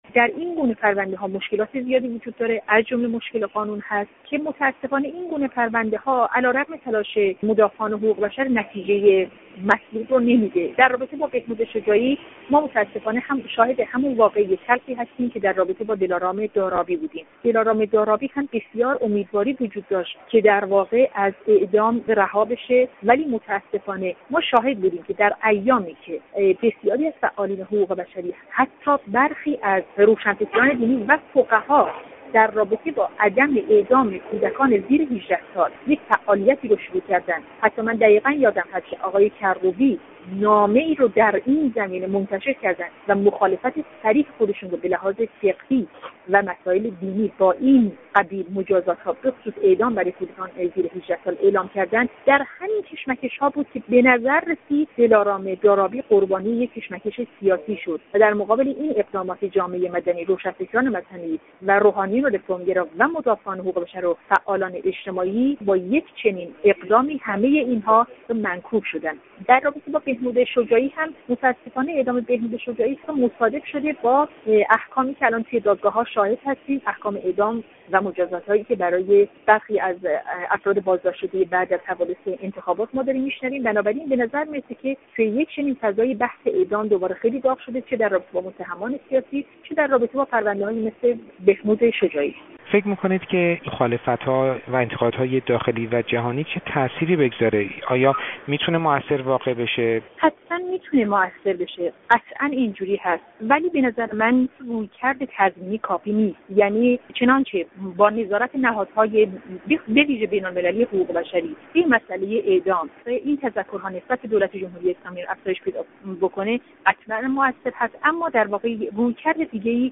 گفت‌وگو با نرگس محمدی، از اعضای کانون مدافعان حقوق بشر در تهران